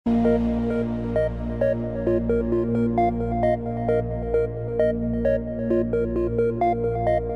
• Качество: 128, Stereo
Electronic
электронная музыка
спокойные
без слов
Trance